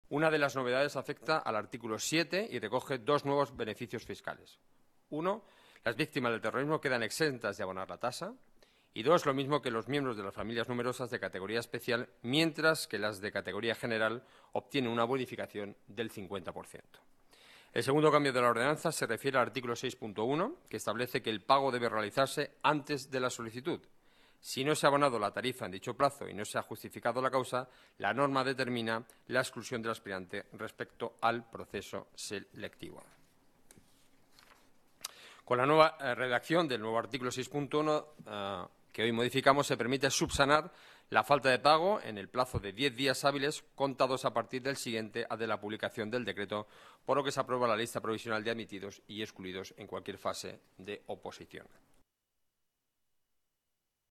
Nueva ventana:Declaraciones portavoz Gobierno municipal, Enrique Núñez, beneficios fiscales en la Tasa de Examen.